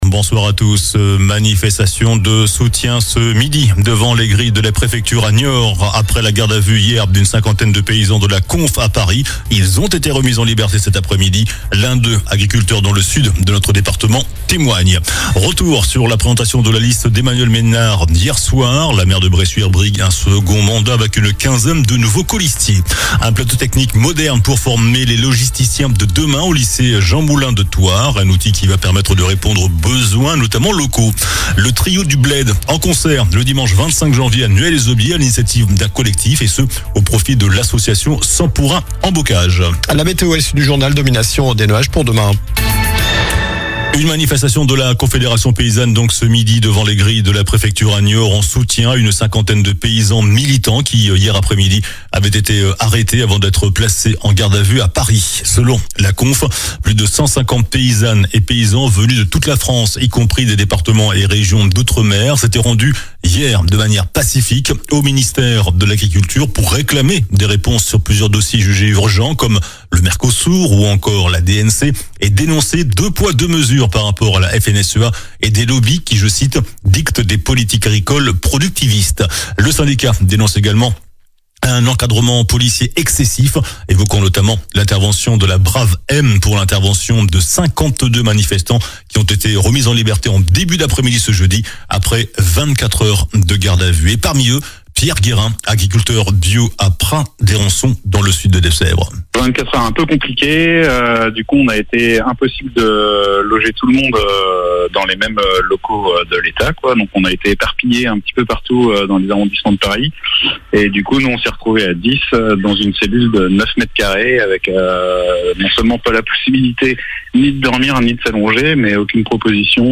JOURNAL DU JEUDI 15 JANVIER ( SOIR )